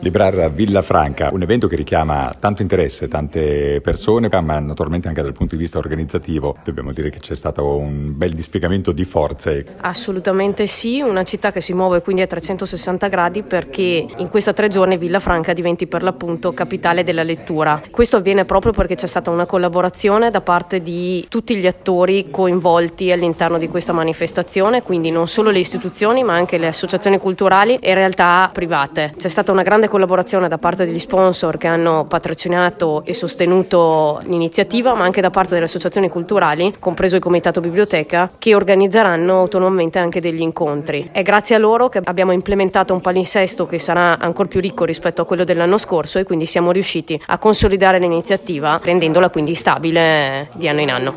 L’Assessore alla Cultura Claudia Barbera: